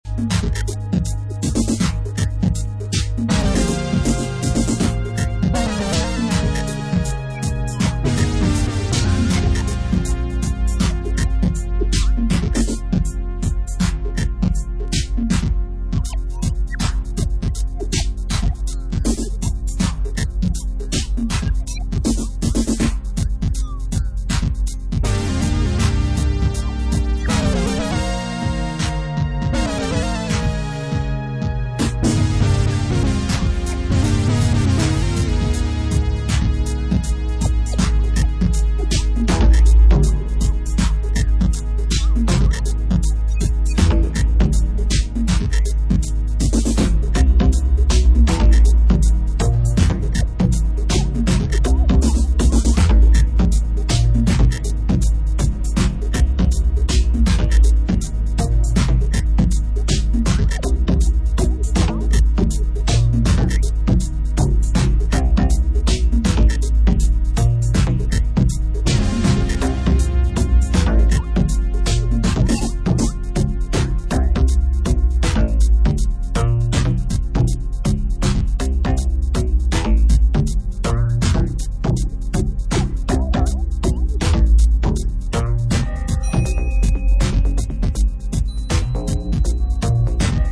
Electro Electronix Techno Vintage Bass Drum and Bass